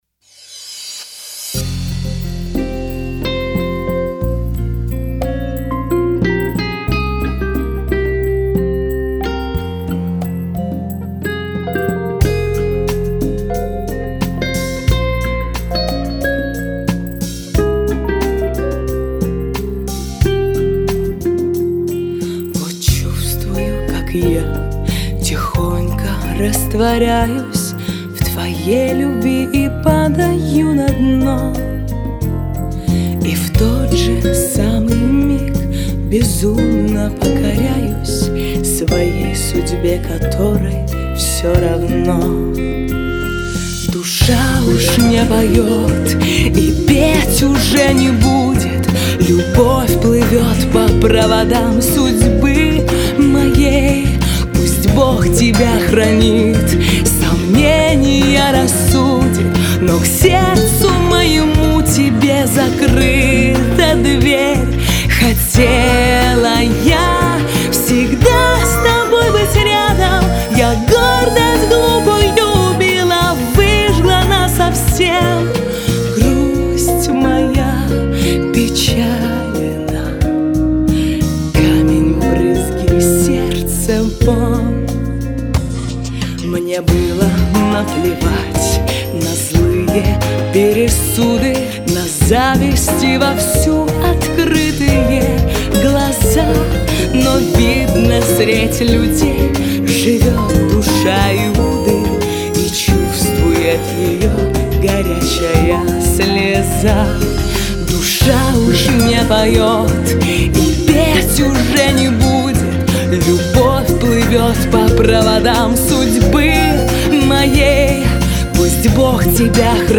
[PreRELEASE]Грусть моя(Романс)